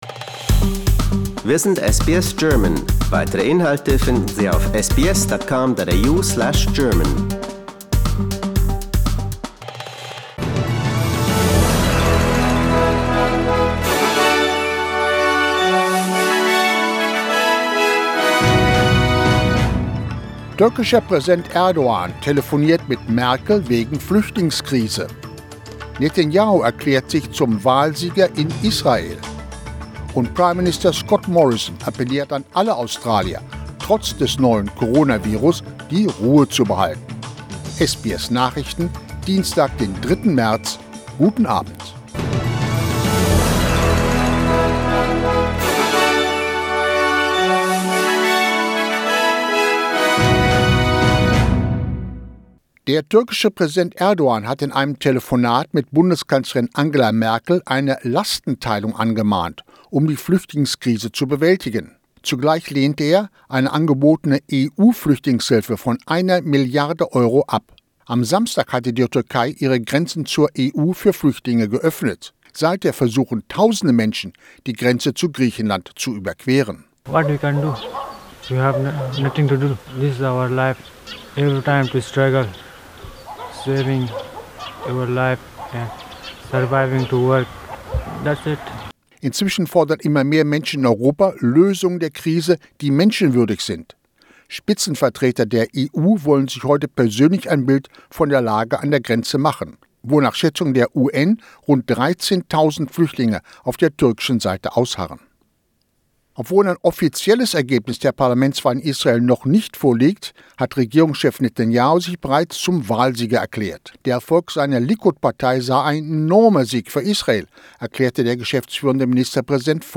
SBS Nachrichten, Dienstag 03.03.20